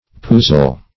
Search Result for " puzzel" : The Collaborative International Dictionary of English v.0.48: Puzzel \Puz"zel\ (p[u^]z"z[e^]l), n. [Cf. F. pucelle a virgin.]